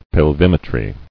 [pel·vim·e·try]